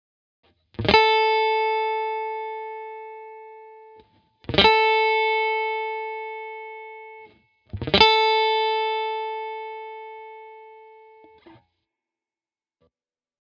Guitar Rake Technique – Advanced Guitar Technique
The pick grazes the strings in a downward motion from ceiling to floor.
The other strings will be muted, so they sound dead (leading to a percussive ‘click’) when the pick hits them.
If you were playing a note on the high e-string (for instance at the 8th fret), you would mute additional strings that are adjacent to that string.